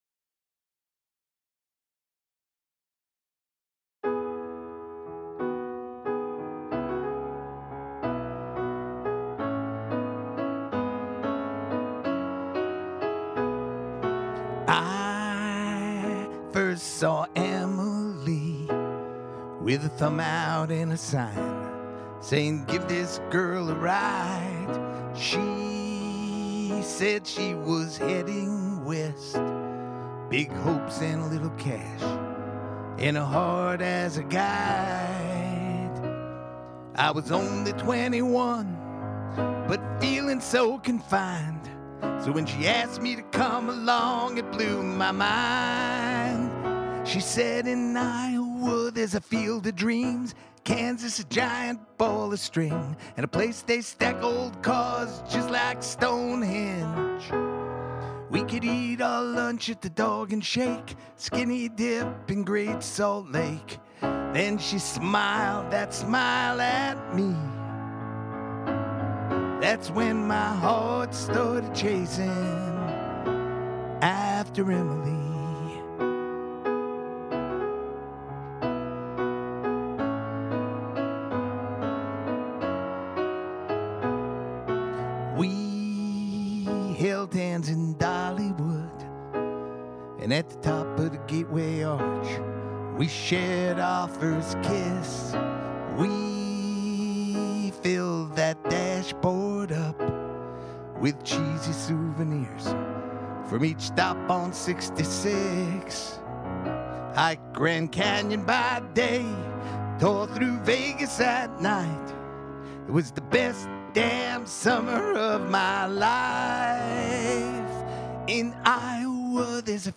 road song